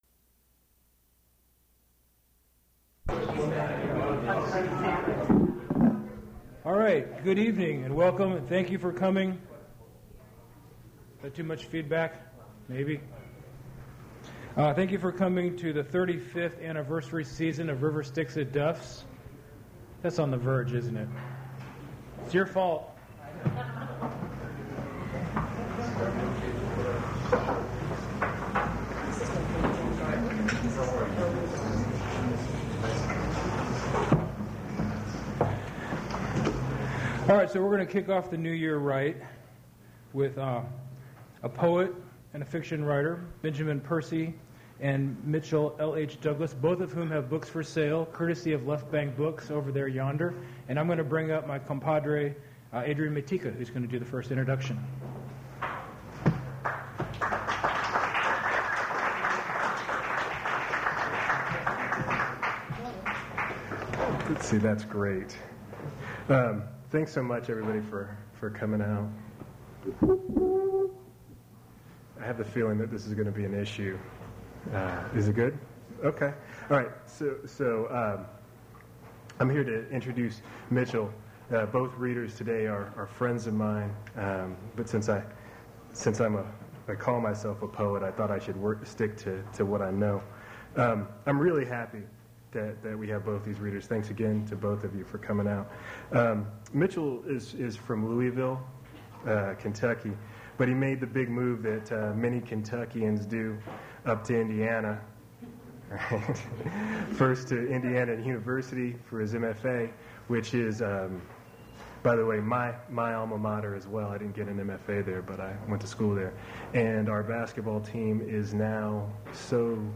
preservation WAV file that was generated from original audio cassette
Cut part of outro & background noise